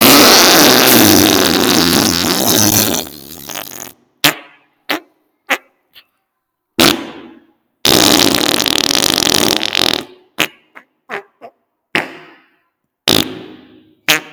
Big Fart Efeito Sonoro: Soundboard Botão
Big Fart Botão de Som
Play and download the Big Fart sound effect buttons instantly!